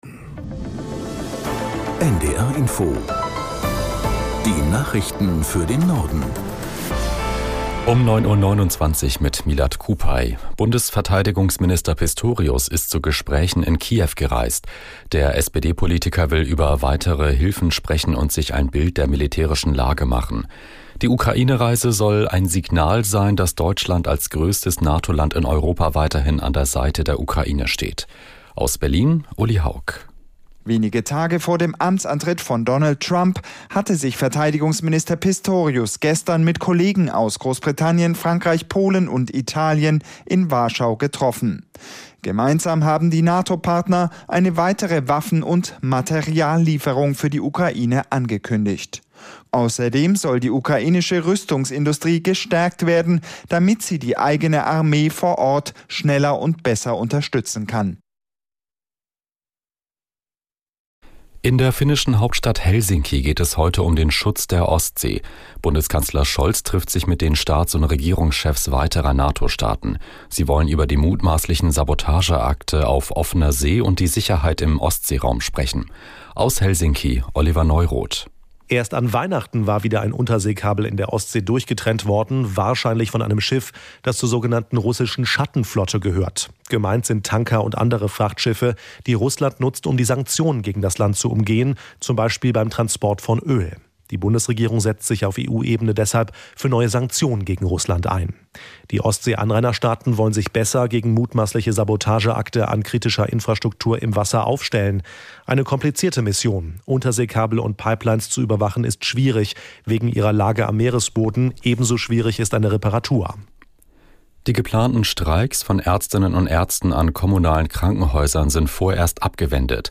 Nachrichten - 14.01.2025